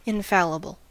Ääntäminen
Vaihtoehtoiset kirjoitusmuodot (vanhentunut) unfallible Synonyymit faultless indefective perfect Ääntäminen US UK : IPA : /ɪnˈfa.lɪ.b(ə)l/ US : IPA : /ɪnˈfæ.lə.bəl/ Tuntematon aksentti: IPA : /ɪnˈfæ.lɪ.bəl/